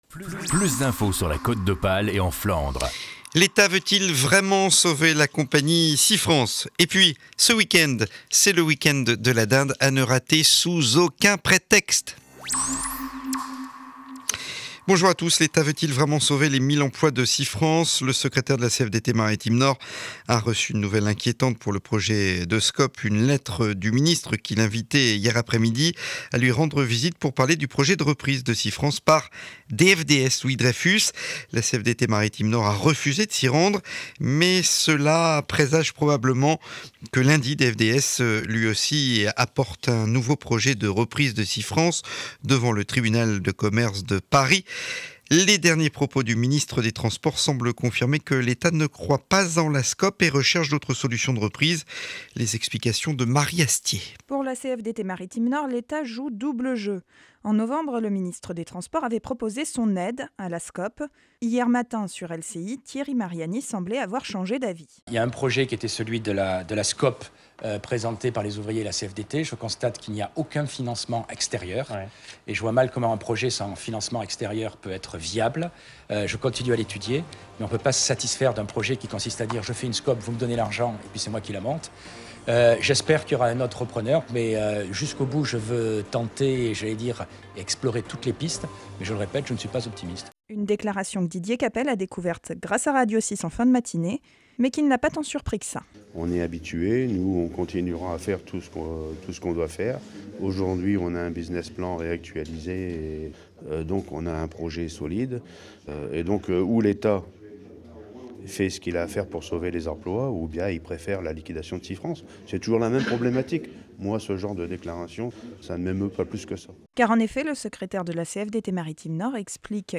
Journal de 12h00 du Vendredi 9 Décembre, édition de Calais.